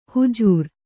♪ hujūr